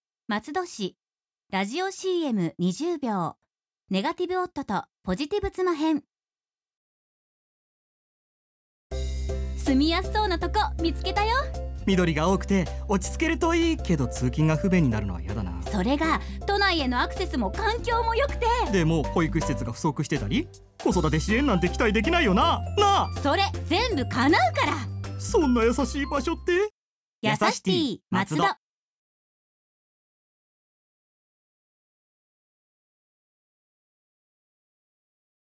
CM収録の様子
ラジオCM放送概要
• ネガティブな夫の不安も吹っ飛ぶ?!　共働き子育てファミリーにやさしい街「松戸」の暮らしやすさを、息ぴったりの2人がテンポ良くPRします